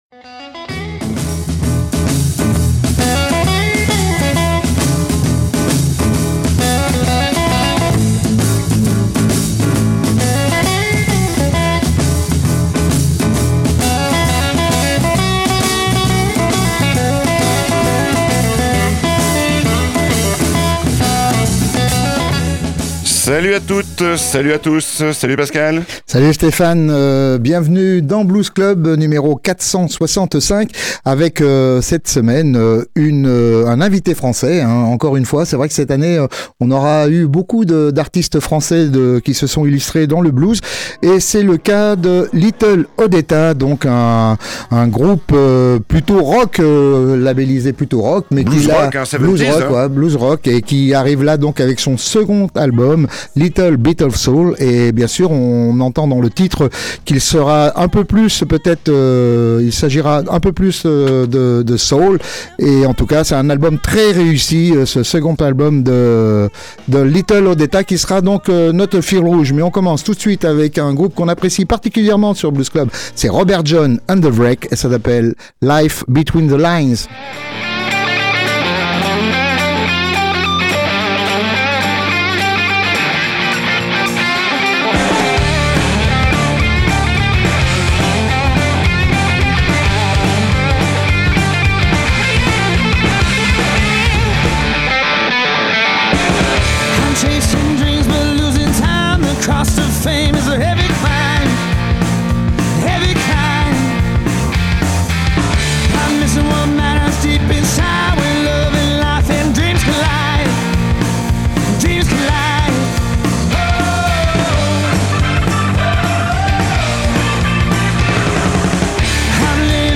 De l’âme, du rythme, de l’intensité, de la maîtrise
Blues Rock
qui lorgne vers le rock 70’s sans en rester prisonnier